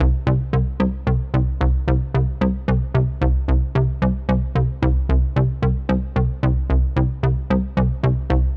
C - Pulsing Bass01.wav